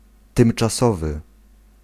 Ääntäminen
Synonyymit éphémère passager temporaire Ääntäminen France: IPA: [pʁɔ.vi.zwaʁ] Haettu sana löytyi näillä lähdekielillä: ranska Käännös Ääninäyte 1. tymczasowy {m} Suku: f .